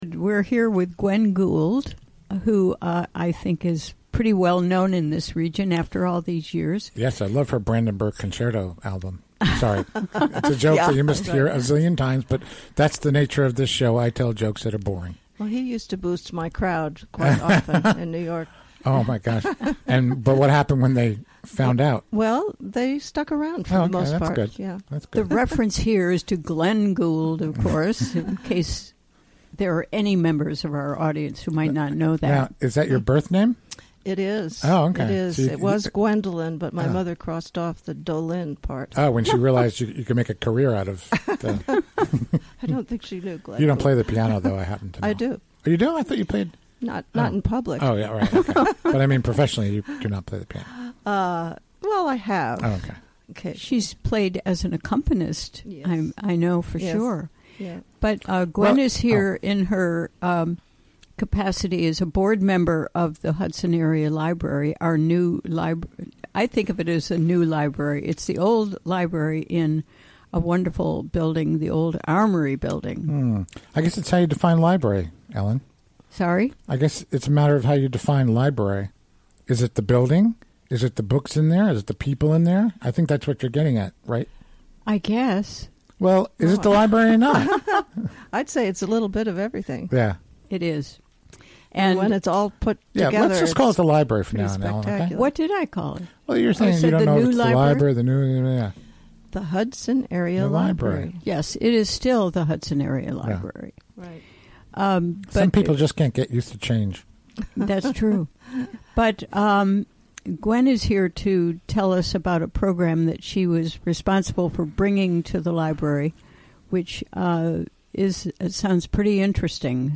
Recorded during the WGXC Afternoon Show Thursday, March 16, 2017.